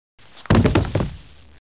thump.mp3